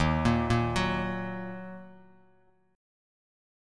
Ebm Chord
Listen to Ebm strummed